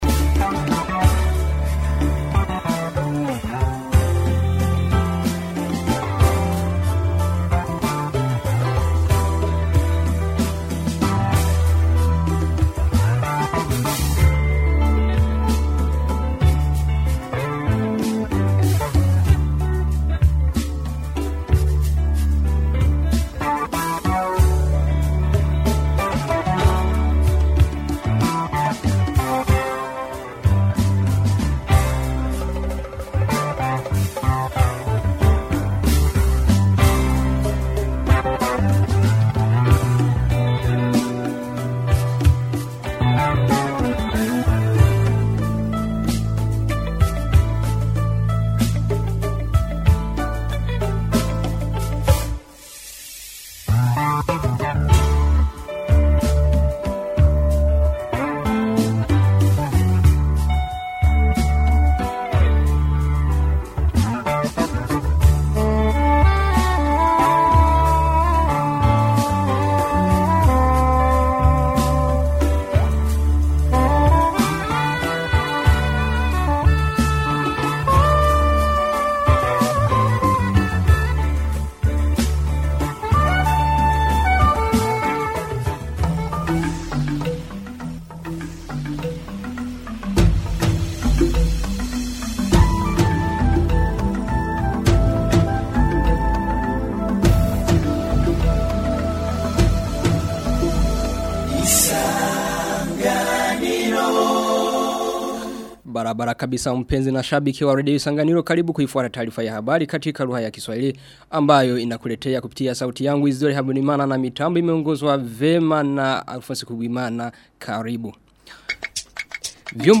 Taarifa ya habri ya tarehe 22 Aprili 2020